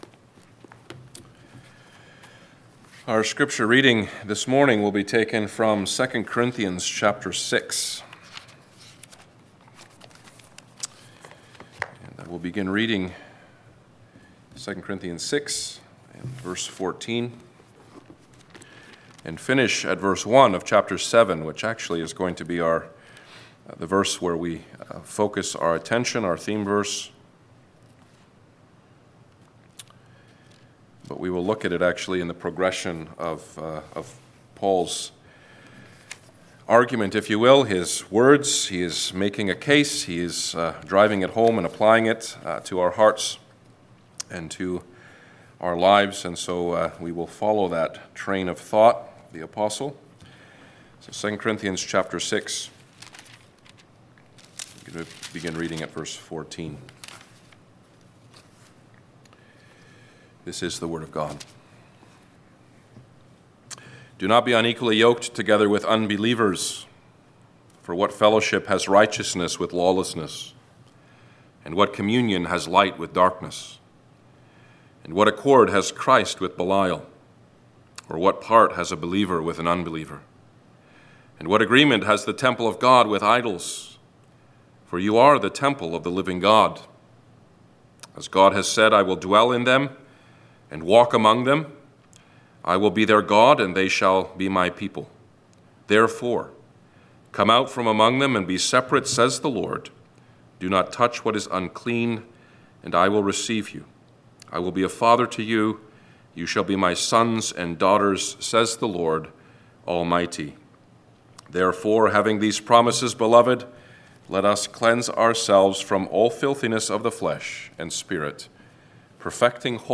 Passage: 2 Cor 6:14-7:1 Service Type: Sunday Afternoon